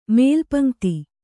♪ mēlpaṇ'gti